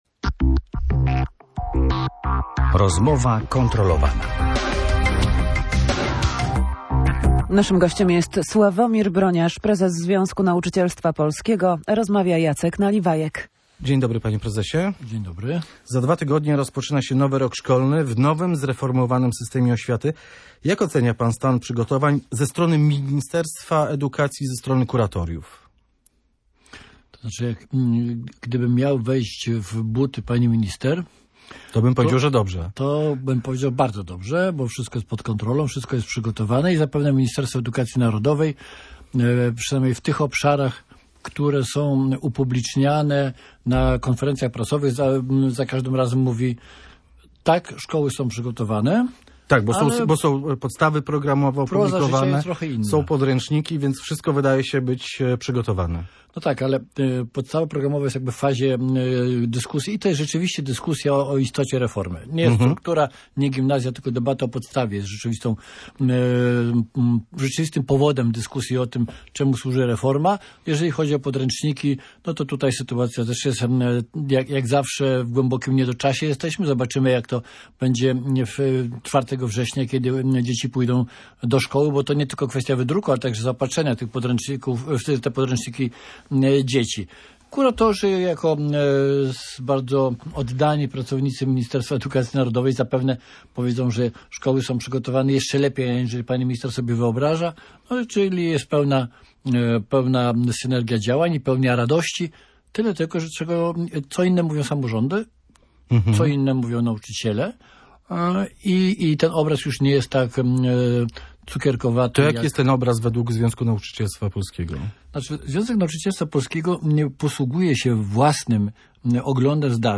Dwa tygodnie zostały do rozpoczęcia nowego roku szkolnego w zreformowanym systemie oświaty. Sławomir Broniarz w Radiu Gdańsk krytycznie ocenił stan przygotowań ze strony ministerstwa edukacji i kuratoriów oświaty.